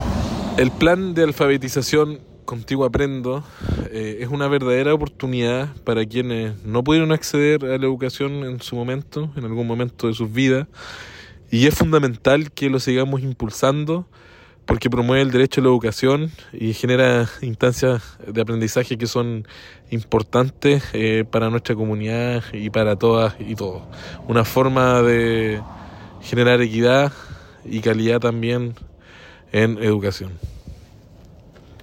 El Seremi de Educación, Nicolás Pérez Allendes, destacó la importancia de esta iniciativa, expresando que